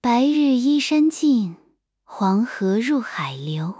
Spark TTS is a cutting-edge text-to-speech model that excels in voice cloning and custom voice creation.
"mode": "voice_creation",
"pitch": "high",
"speed": "low",
"gender": "female",
generated_speech.wav